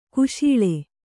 ♪ kuśiḷe